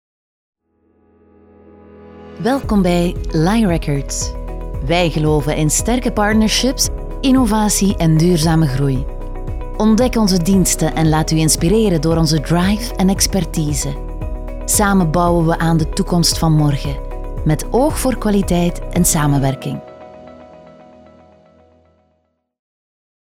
Natural, Cálida, Suave, Accesible, Amable
Corporativo